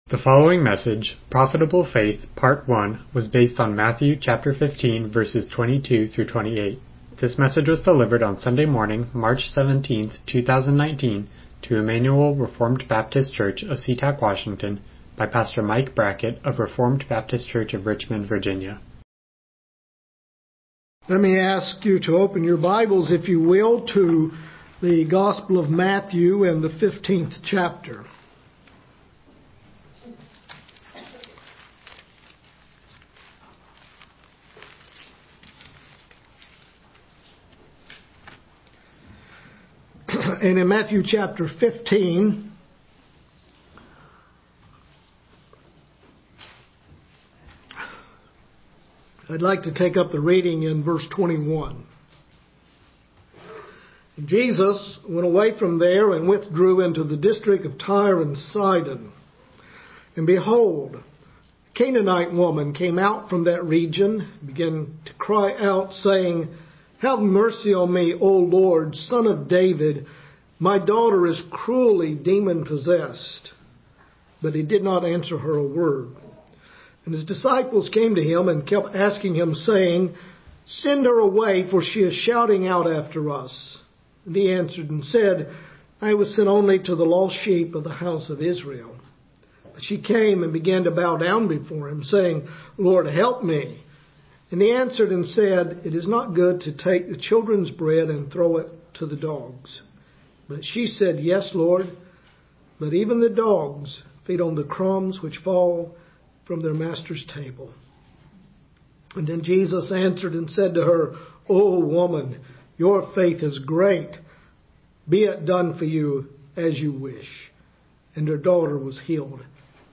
Miscellaneous Passage: Matthew 15:22-28 Service Type: Morning Worship « Dealing With Sinful Anger Profitable Faith